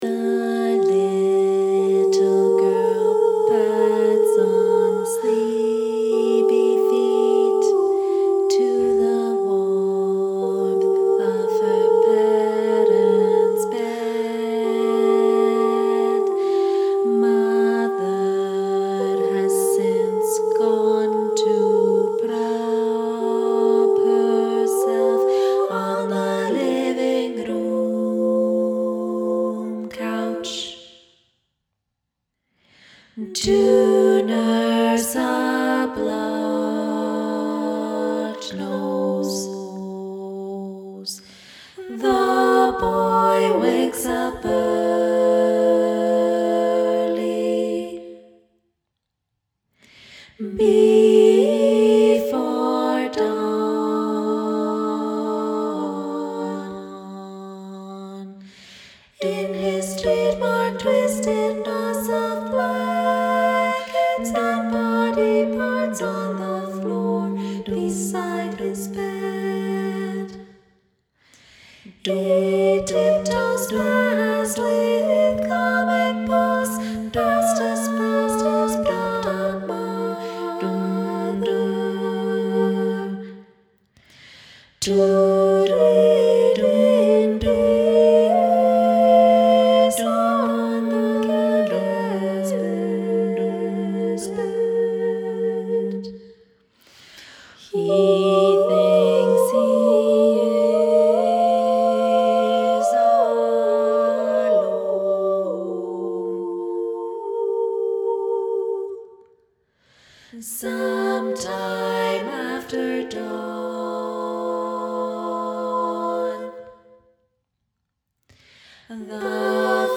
Musical Beds: choral for SSA